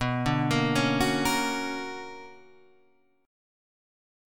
BmM9 chord {7 5 8 6 7 6} chord